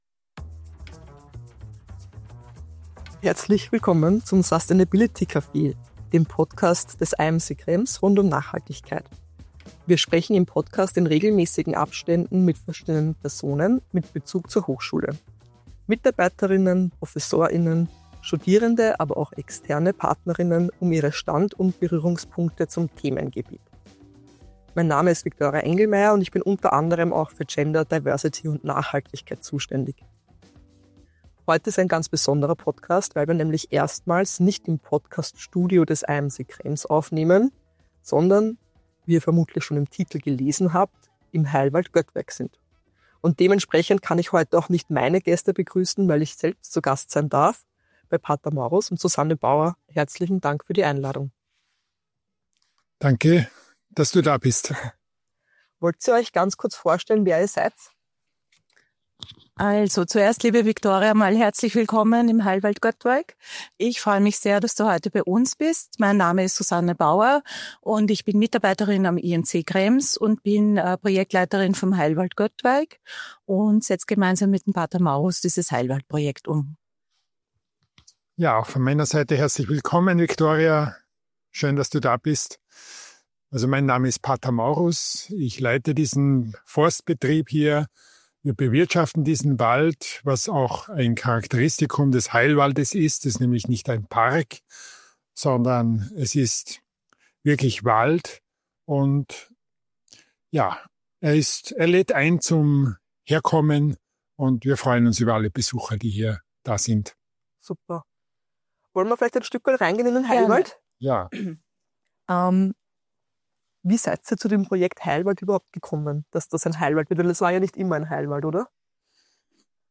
Bäume, Balance & ein bisschen Bibel: Heilwald Göttweig im Gespräch ~ IMC Sustainability Café Podcast